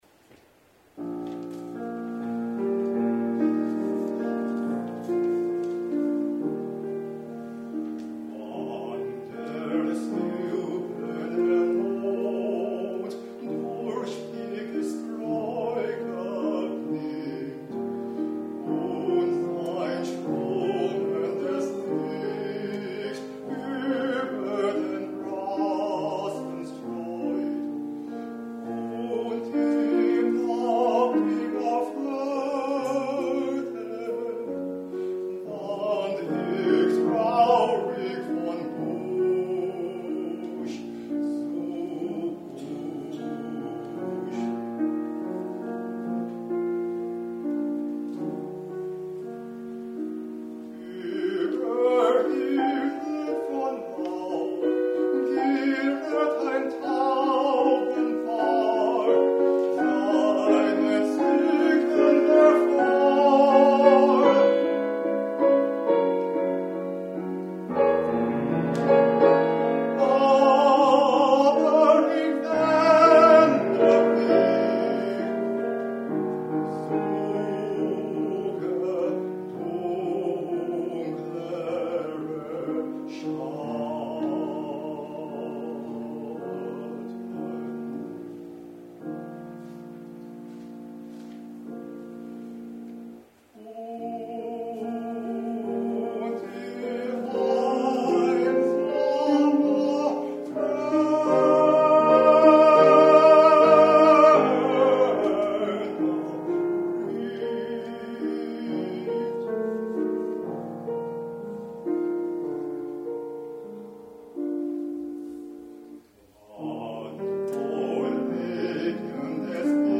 Piano accompaniment
Music group concert
baritone